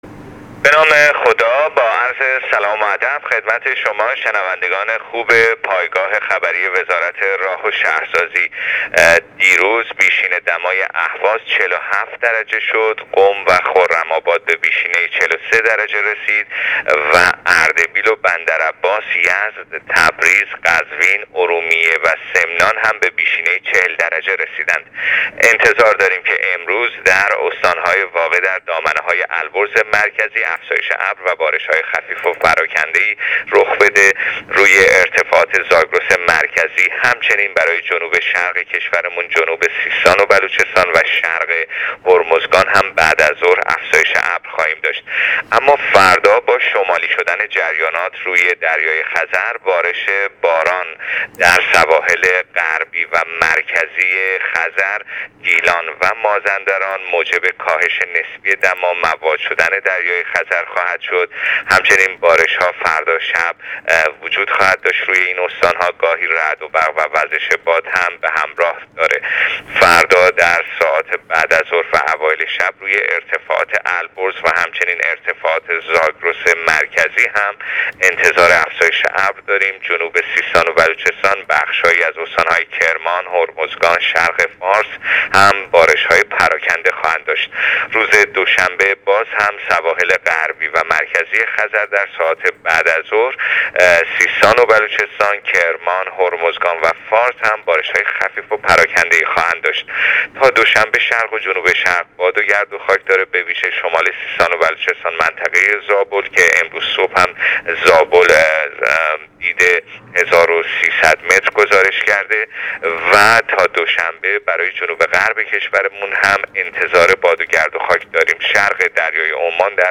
گزارش آخرین وضعیت جوی کشور را از رادیو اینترنتی پایگاه خبری وزارت راه و…